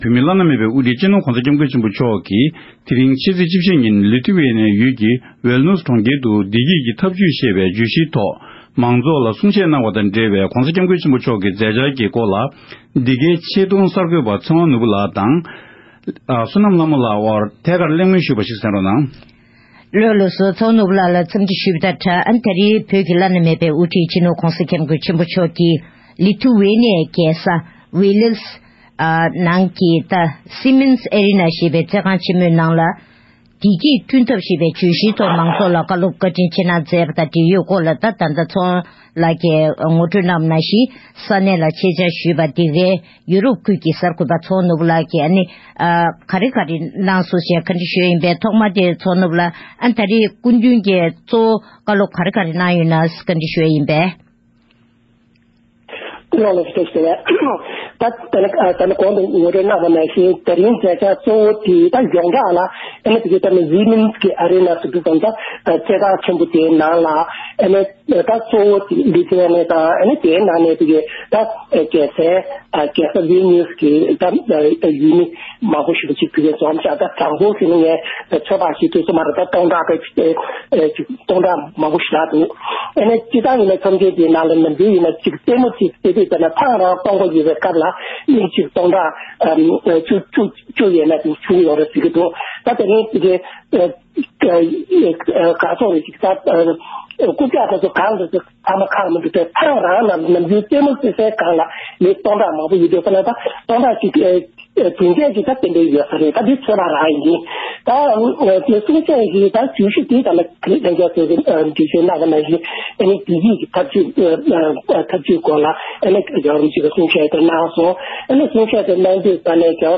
དེ་ཡང་ཕྱི་ཟླ་ ༦ ཚེས་ ༡༤ ཉིན་༧གོང་ས་སྐྱབས་མགོན་ཆེན་པོ་མཆོག་གིས་ཡོ་རོབ་སྦལ་ཊིཀ་མངའ་ཁུལ་ཡུལ་གྲུ་ལི་ཐུཡེ་ནི་ཡའི་རྒྱལ་ས་ཝིལ་ནིཡུསྀ་ནང་གི་སིཡེ་མནསྀ་ཚོགས་ཁང་ཆེན་མོའི་ནང་མང་ཚོགས་སྟོང་ཚོ་འགའ་ཤས་ལ་བདེ་སྐྱིད་ཀྱི་ཐབས་ལམ་ཞེས་པའི་བརྗོད་གཞིའི་སྒོ་ནས་བཀའ་སློབ་གསུང་བཤད་སྩལ་སྐབས།